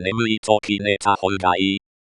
pronunciation ne̞ mɯ̟ᵝ i to̞ ki ne̞ ho̞ː [laughter]
You can hear eSpeak NG read the full sentence (minus the laughter…) here.